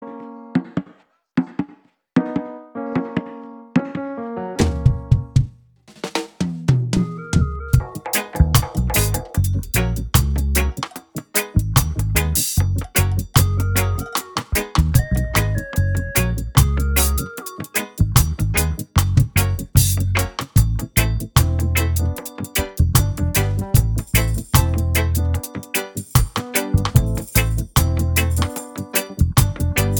Genre: Voiceless